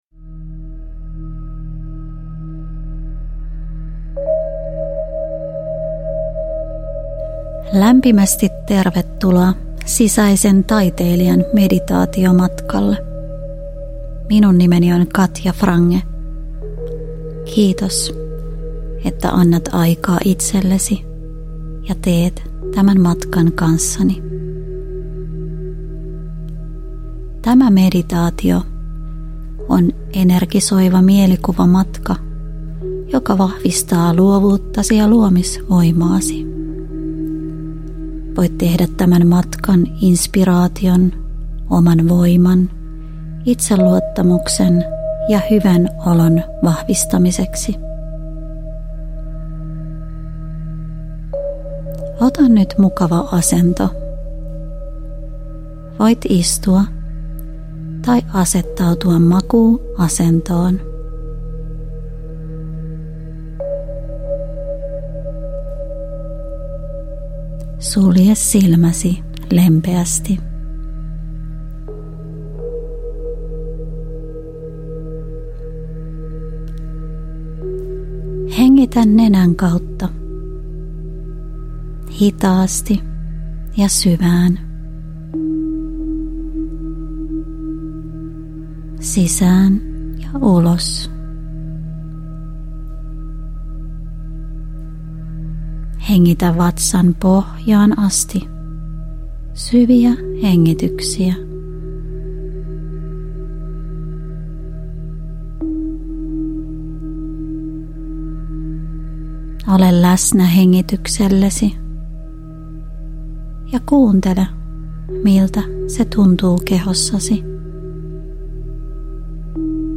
Sisäinen taiteilija -meditaatio – Ljudbok – Laddas ner
Tämä meditaatio on energisoiva mielikuvamatka, joka herättelee luovuuttasi ja vahvistaa luomisvoimaasi.